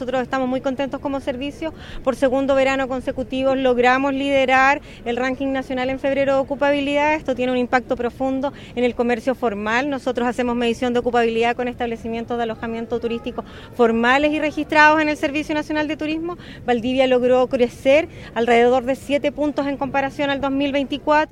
En ese contexto, la directora del Sernatur en Los Ríos, Marila Barrientos, indicó que la ciudad experimentó un aumento de 7 puntos en ocupabilidad, en comparación con el año anterior.